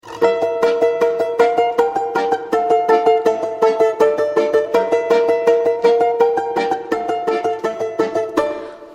Балалайка